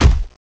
eyx_run_step02.mp3